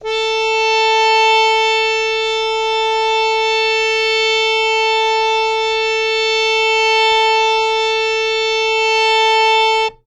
harmonium
A4.wav